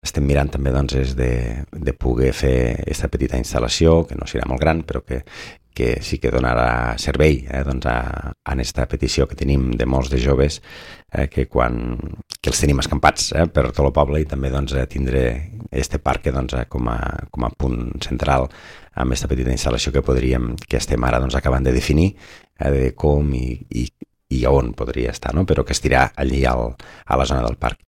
Jordi Gaseni és l’alcalde de l’Ametlla de Mar: